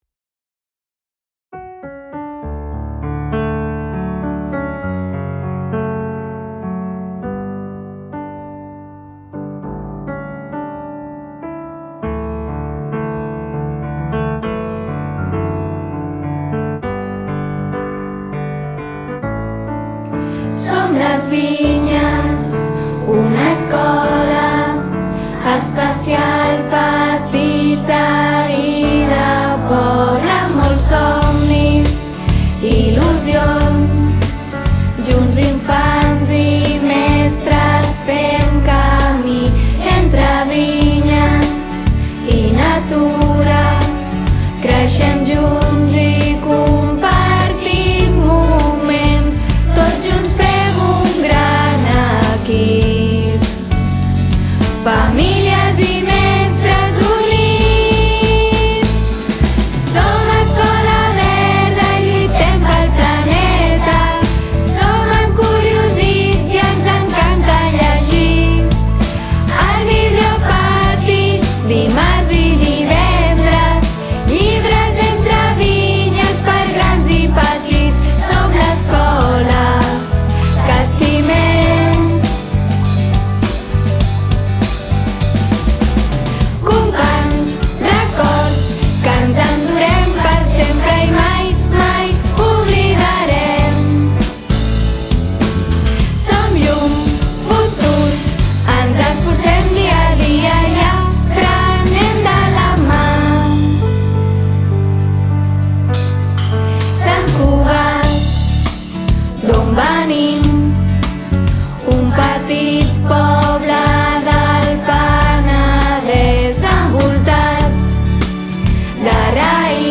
Hem pogut gaudir de l’experiència d’enregistrar-la en un estudi de gravació professional i fins i tot ser els creadors de la portada d’aquesta.
• Som Les Vinyes (mestra i alumnes)